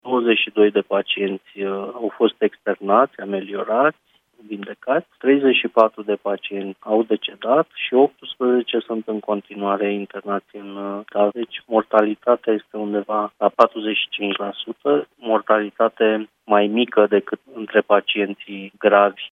S-a observat că plasma nu are deloc efecte adverse, după cum explică la Europa FM, secretarul de stat din ministerul Sănătății, Dragoș Garofil: